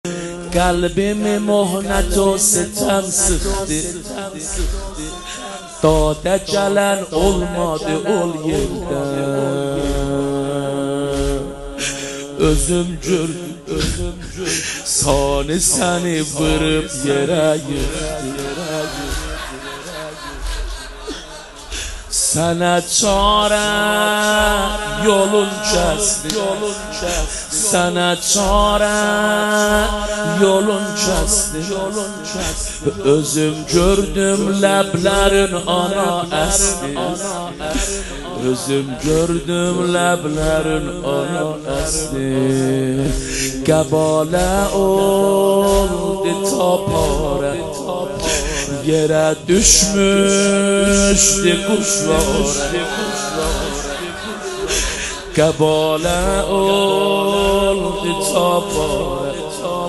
زمینه | داده گلن اولماده الده (کسی آنجا به داد من نرسید)
مداحی